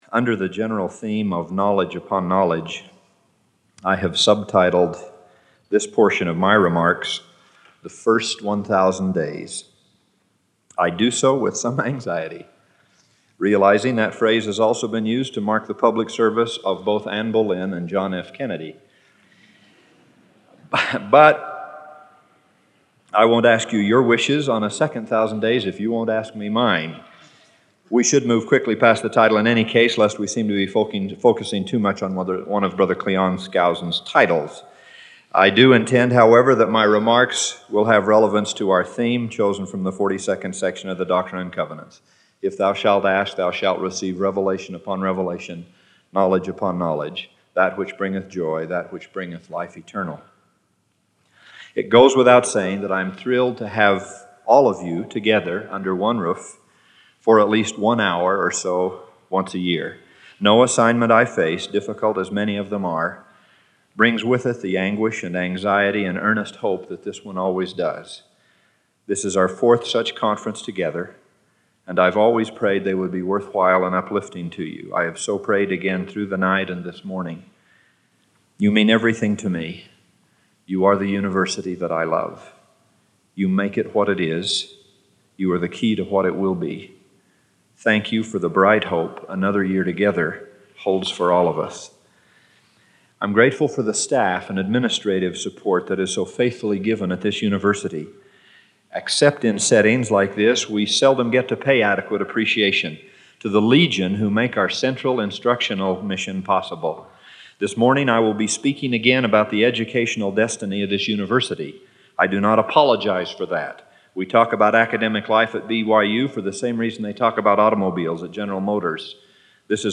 University Conference
Jeffrey R. Holland was president of BYU when he delivered this university conference address on August 31, 1983.